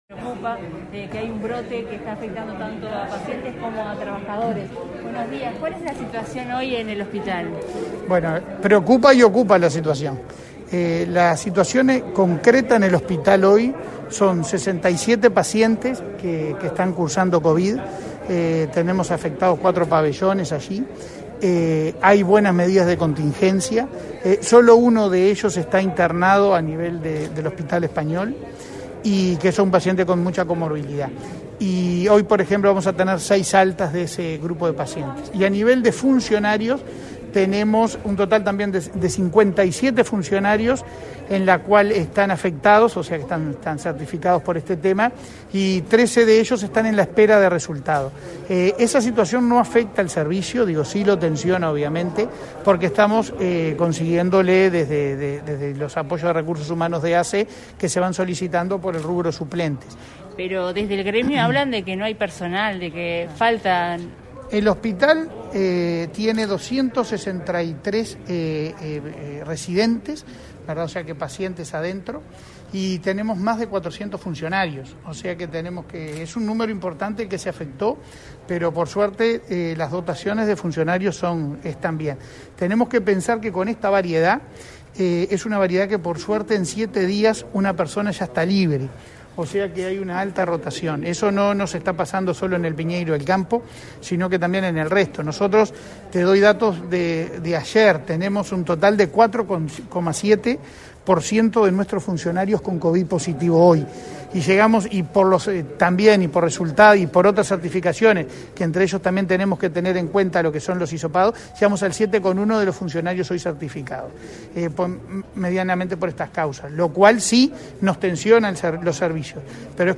Declaraciones del presidente de ASSE, Leonardo Cipriani, a la prensa